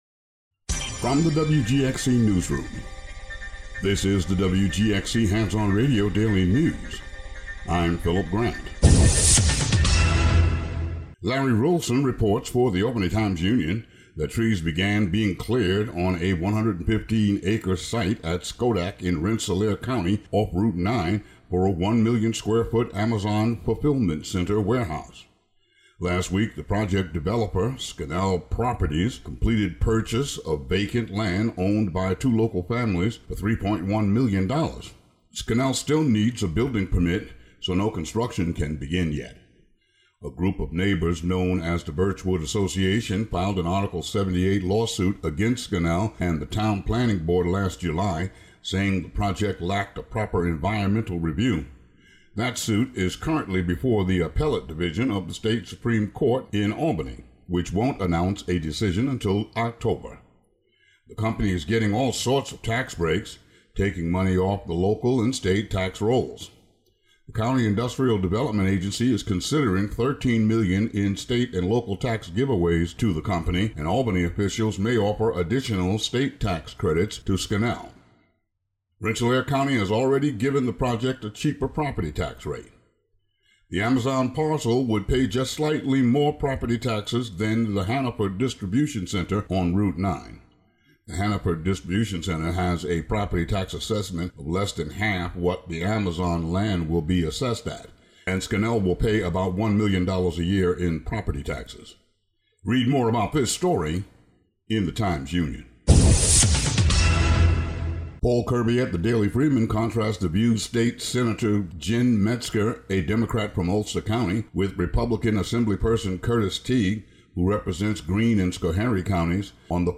Local news.